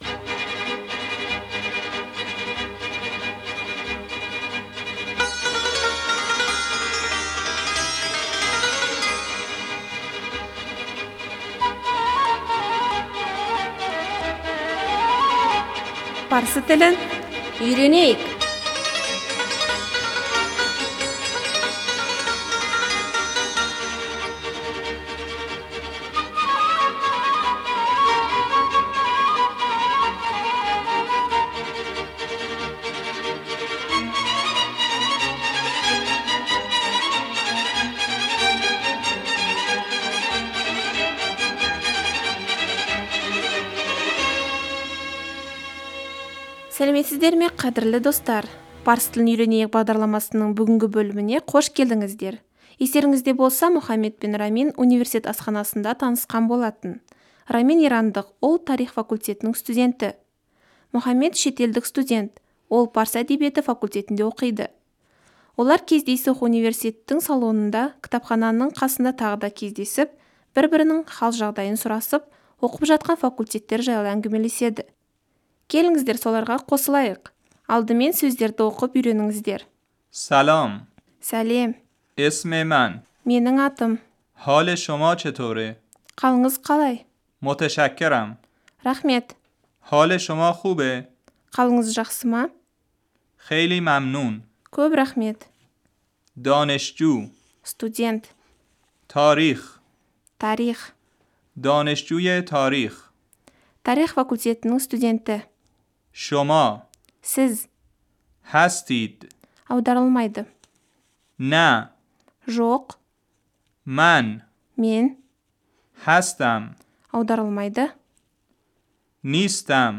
Рамин: Сәлем Мұхаммад ((унив-тің салоныңда студенттедің дауысы)) салам мұхаммад رامين - سلام محمد .